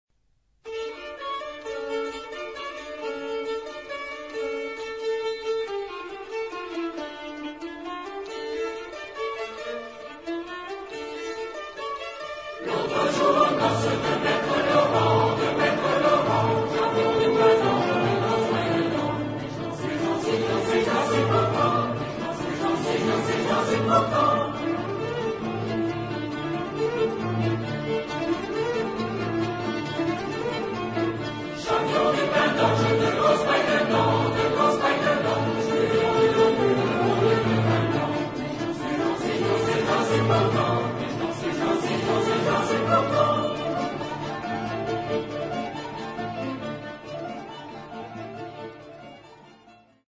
Genre-Style-Form: Secular ; Popular ; Song with repetition
Mood of the piece: perky ; without haste
Type of Choir: SMA  (3 women voices )
Tonality: E minor
Origin: Brittany (F)